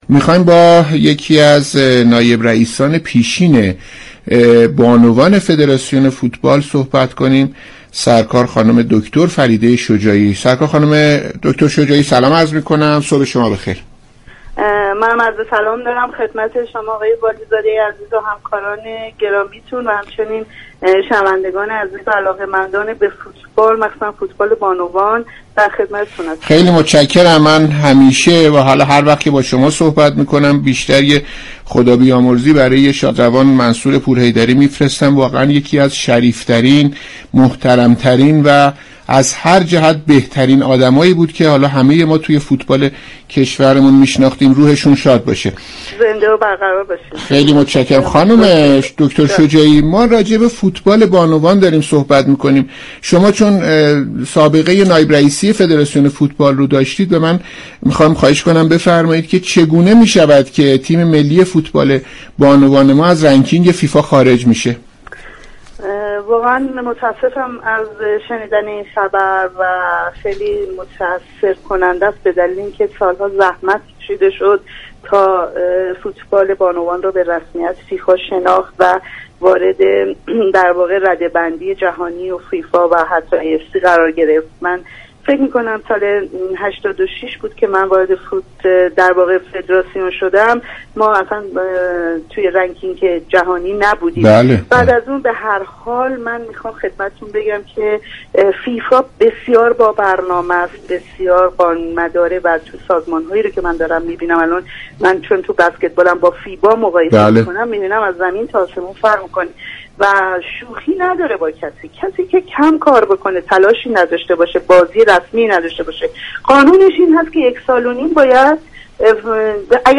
فریده شجاعی، نائب رئیس بانوان سابق فدراسیون فوتبال در برنامه «صبح و ورزش» رادیو ورزش یكشنبه 7 دی به گفتگو در خصوص دلایل افت فوتبال بانوان پرداخت.